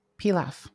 Pilaf (US: /ˈplɑːf/